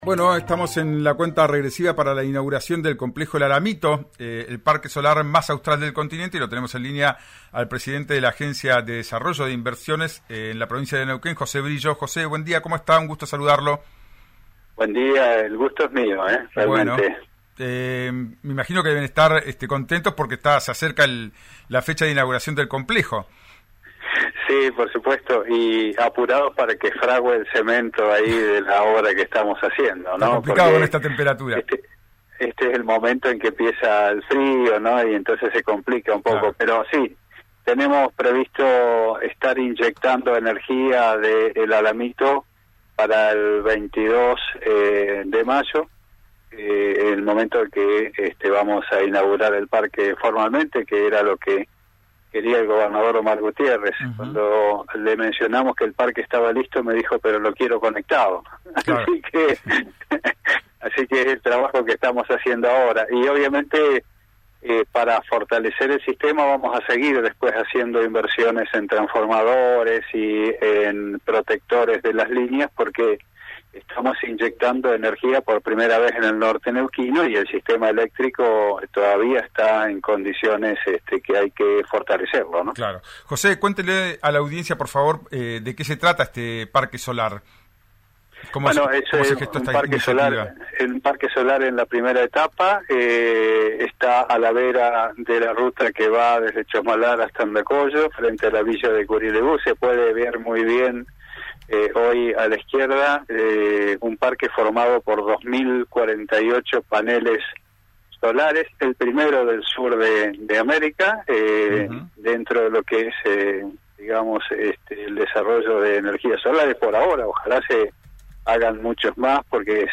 Escuchá al titular de ADI NQN, José Brillo, en RÍO NEGRO RADIO: